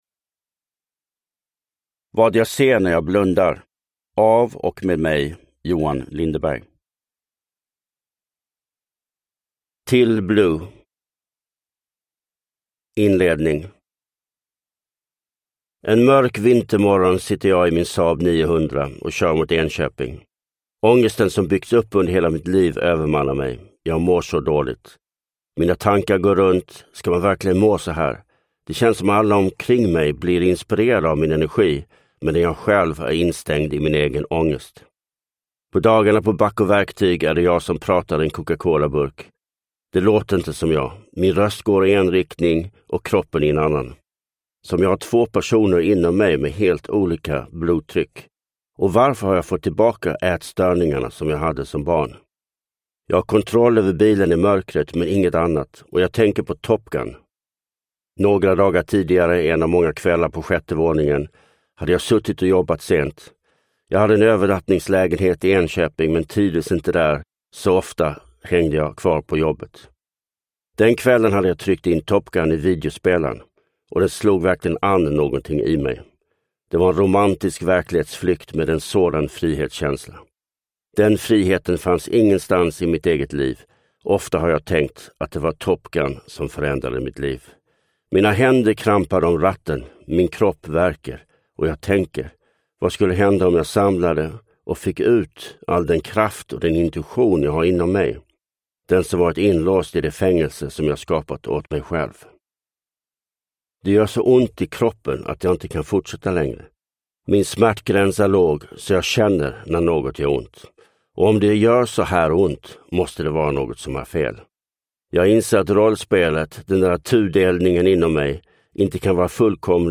Vad jag ser när jag blundar – Ljudbok
Uppläsare: Johan Lindeberg